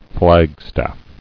[Flag·staff]